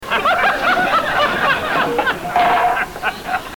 His Laugh
Category: Television   Right: Personal